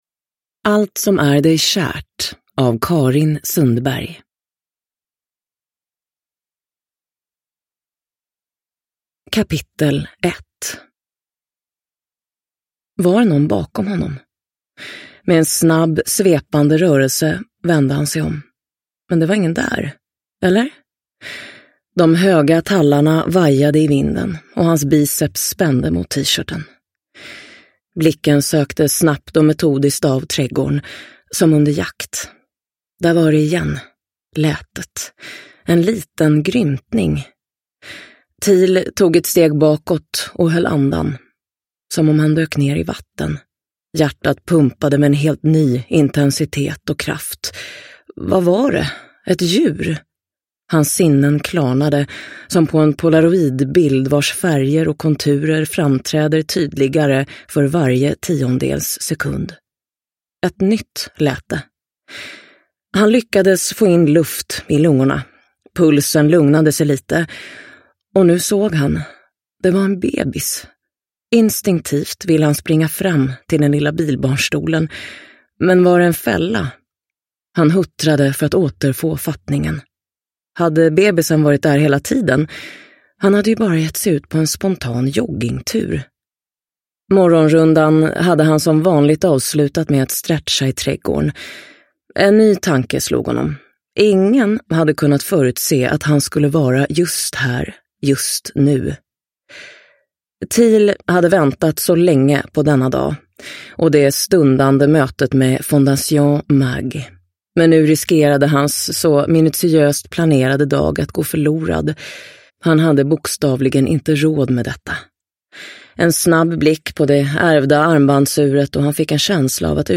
Allt som är dig kärt – Ljudbok – Laddas ner
Uppläsare